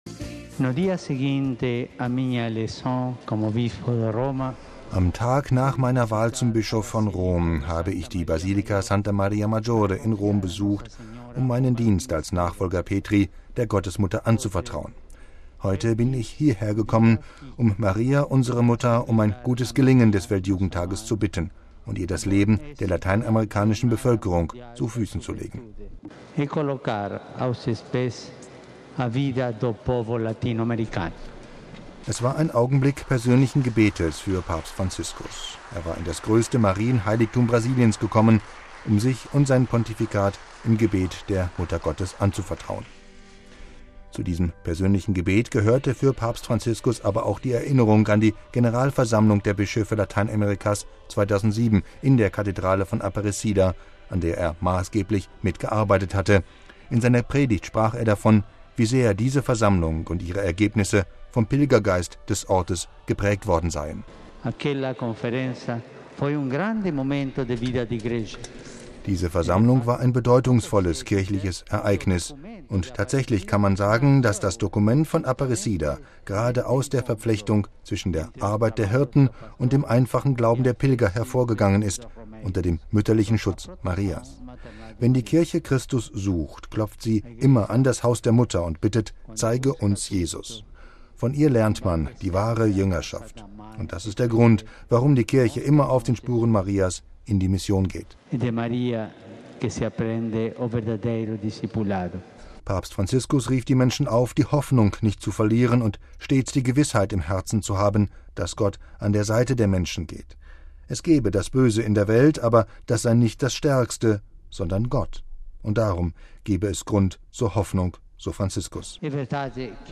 Messe in Aparecida: Von Maria lernen wir wahre Jüngerschaft